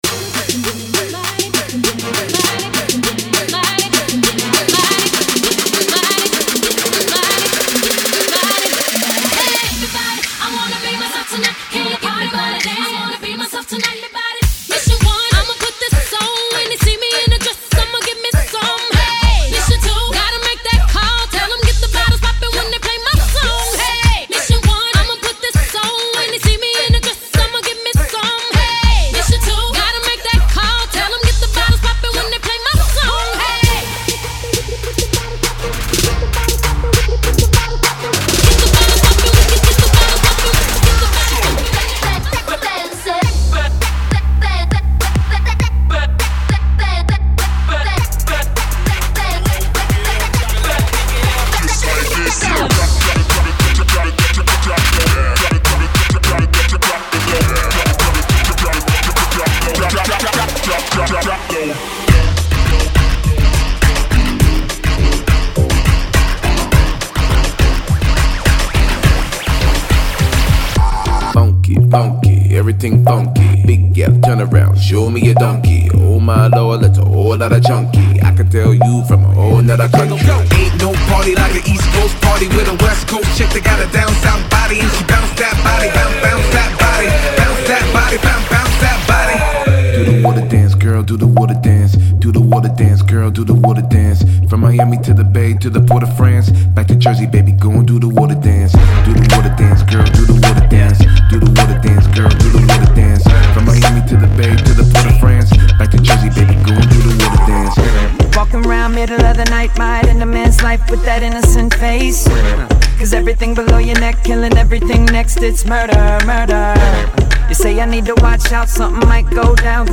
Pop/Hip Hop